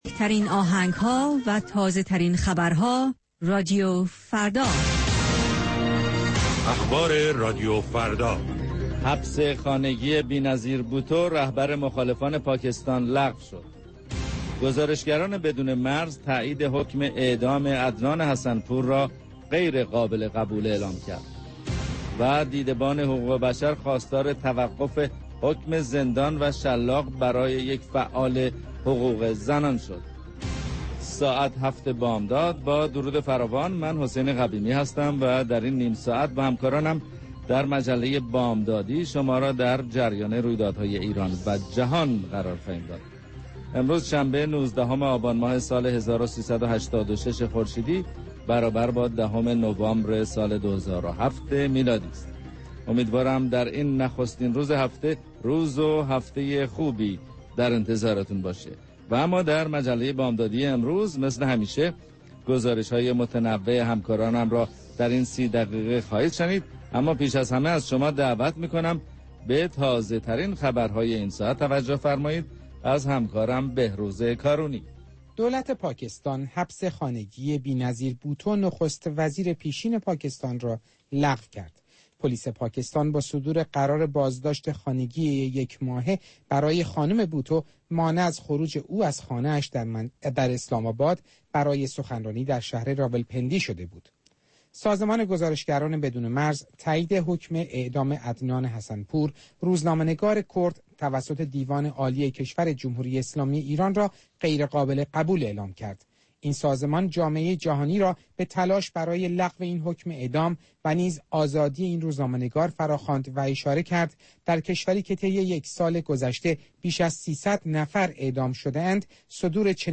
گزارشگران راديو فردا از سراسر جهان، با تازه ترين خبرها و گزارش ها، مجله ای رنگارنگ را برای شما تدارک می بينند. با مجله بامدادی راديو فردا، شما در آغاز روز خود، از آخرين رويدادها آگاه می شويد.